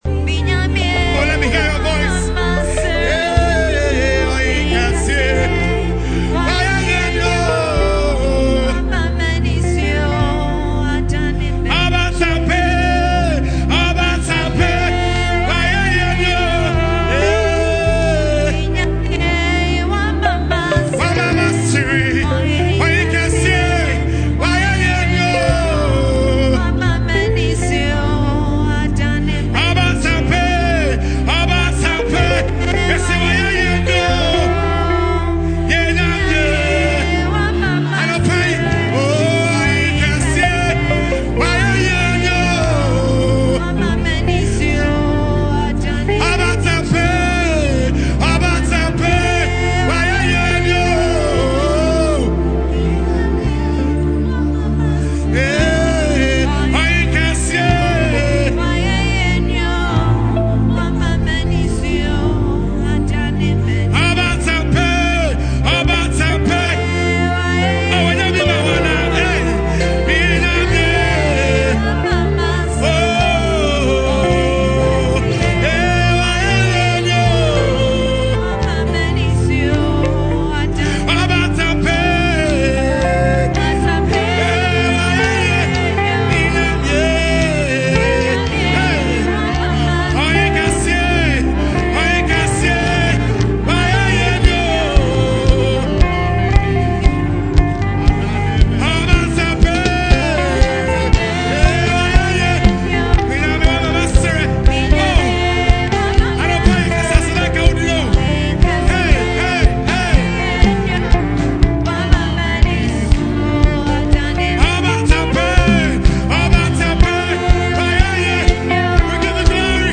Our Song Ministrations: Live Recordings - The Living God Tabernacle
Enjoy Live Recordings Of Inspiring Song Ministrations From The Living God Tabernacle Musicians.